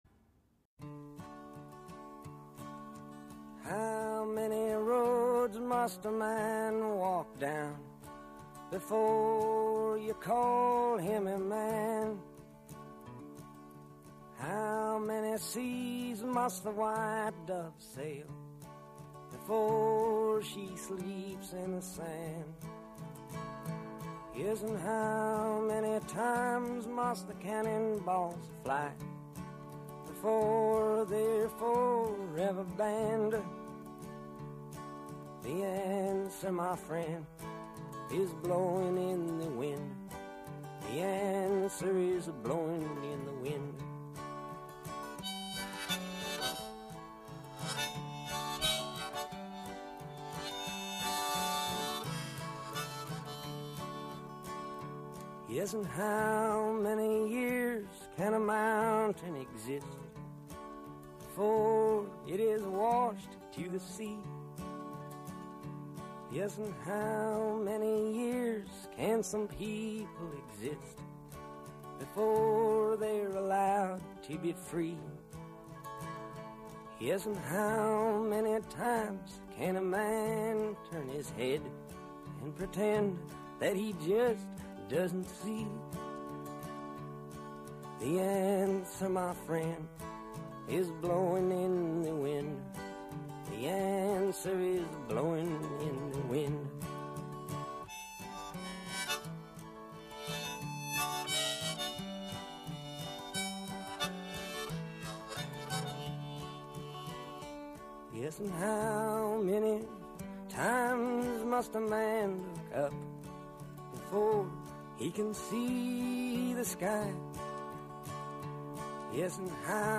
20 Μαρτίου 2018 – Αφιέρωμα ο Αρης Ρέτσος διαβάζει Παπαδιαμάντη